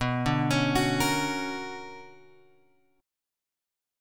B Minor Major 9th